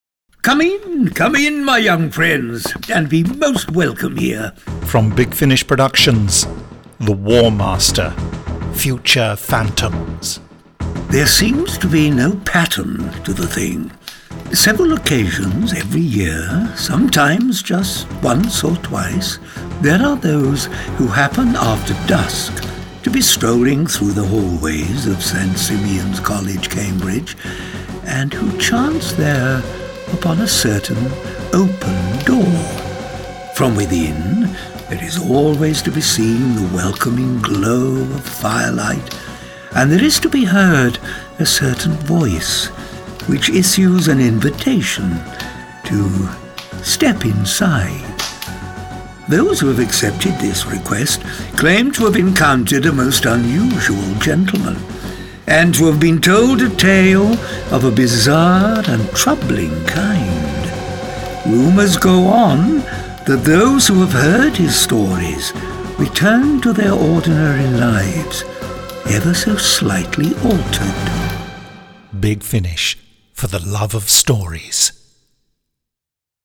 Starring Derek Jacobi